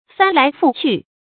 注音：ㄈㄢ ㄌㄞˊ ㄈㄨˋ ㄑㄩˋ
翻來覆去的讀法